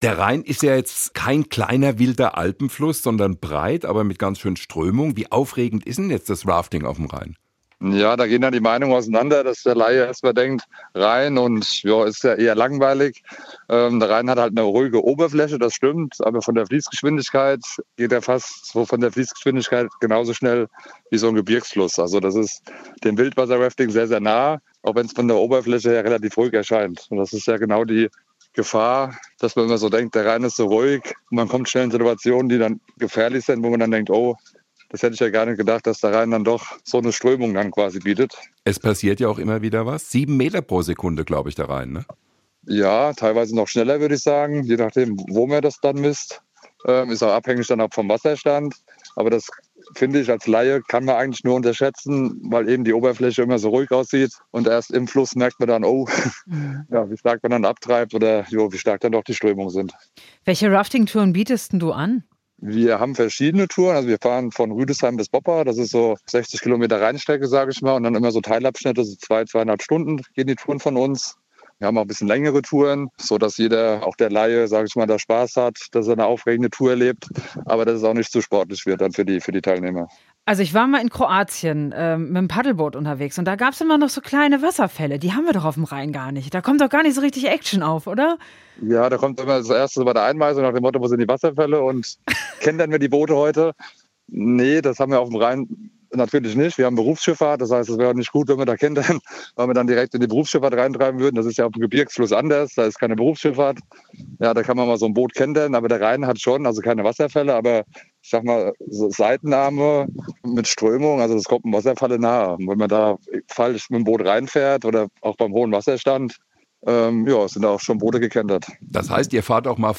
SWR1 Interviews
Interview mit